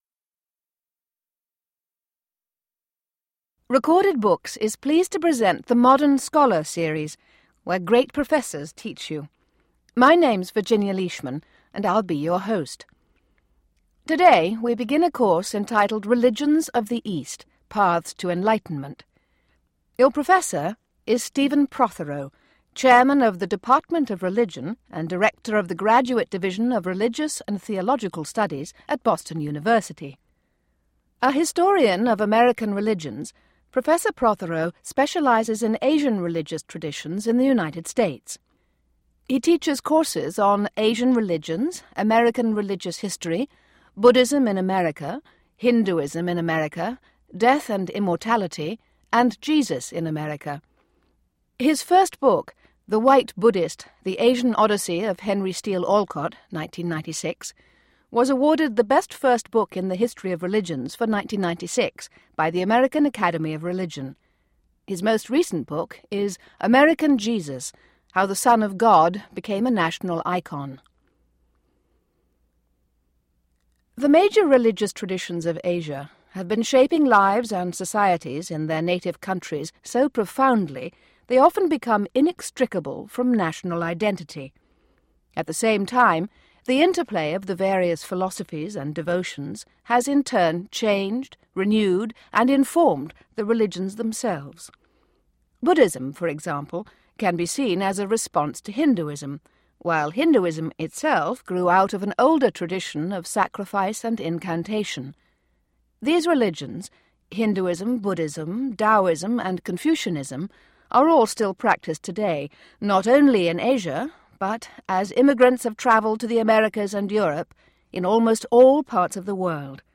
In this lecture Professor Stephen Prothero discusses what religion is and why it still matters in the modern age.